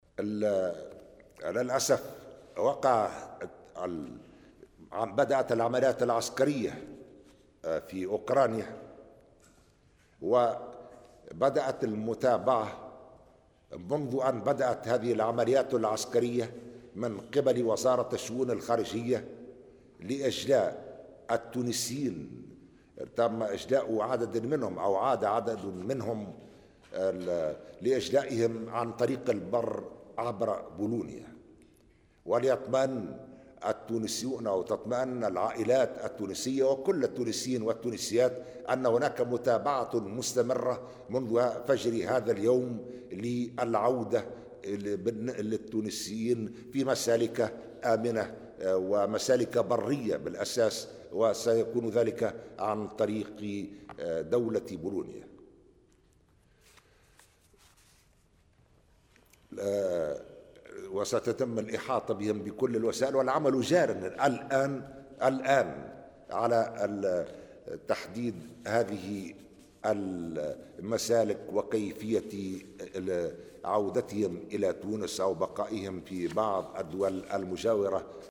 وأضاف خلال إشرافه اليوم على اجتماع مجلس الوزراء، أن العمل متواصل لتحديد المسالك البرية الآمنة من أجل عودتهم إلى تونس أو البقاء في بعض الدول المجاورة.